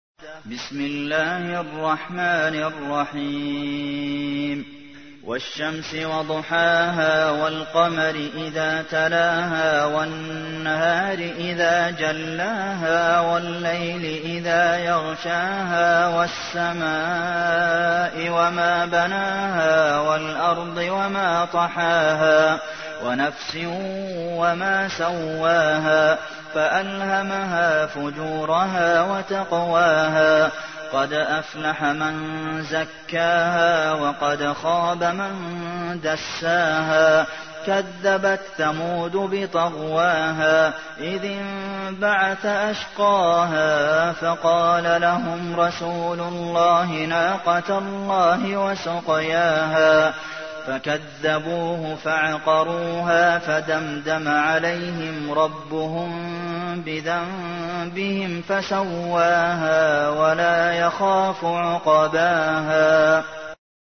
تحميل : 91. سورة الشمس / القارئ عبد المحسن قاسم / القرآن الكريم / موقع يا حسين